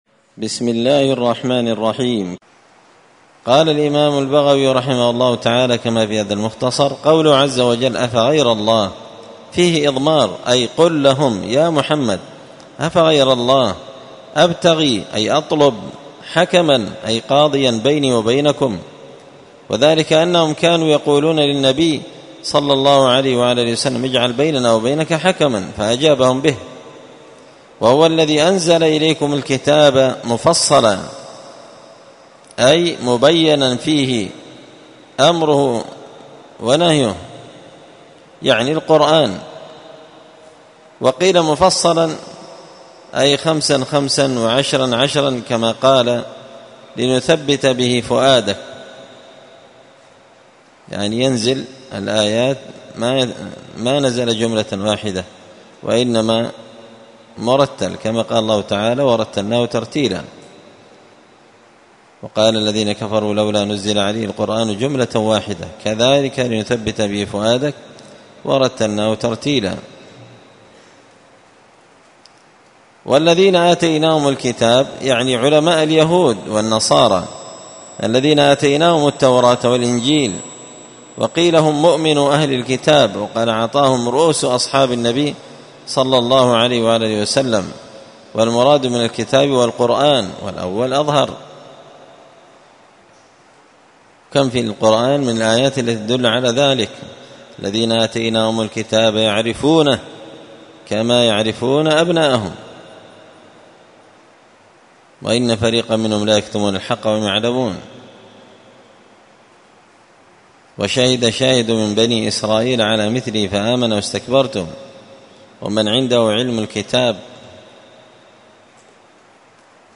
مختصر تفسير الإمام البغوي رحمه الله الدرس 346